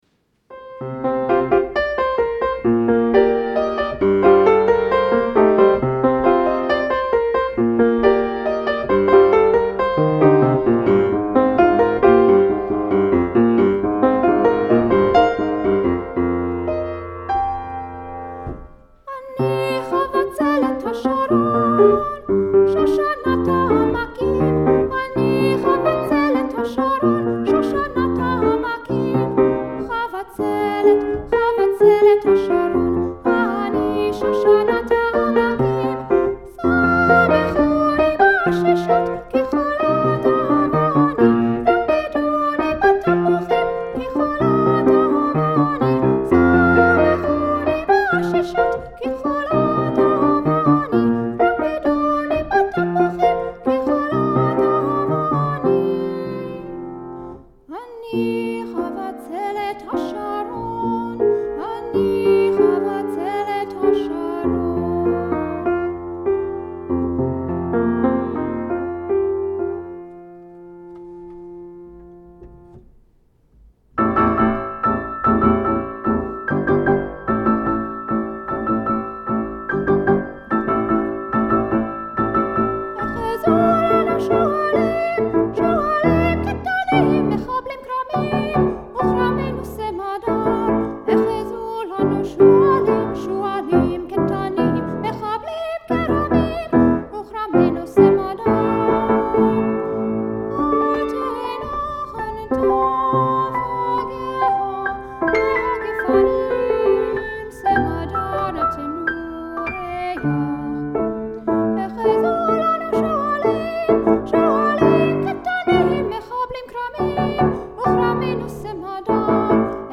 piano (recorded live